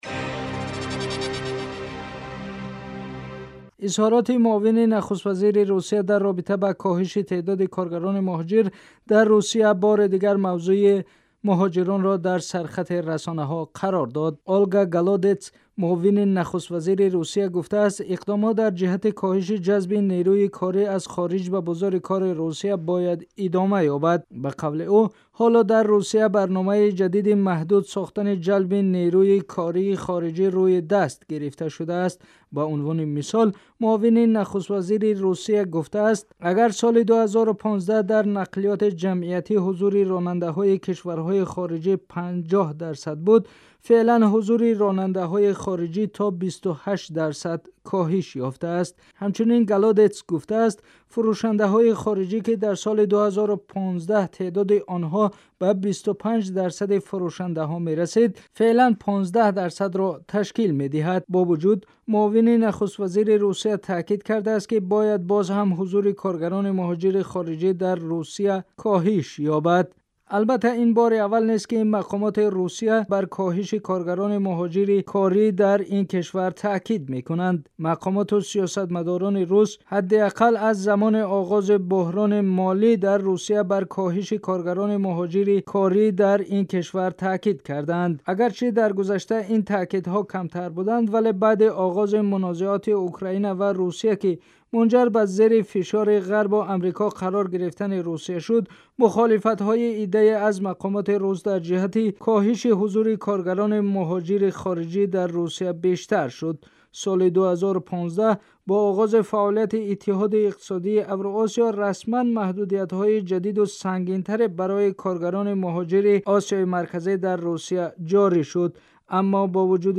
گزارش ویژه : کاهش مهاجران کاری در روسیه و راهکارهای پیشروی تاجیکستان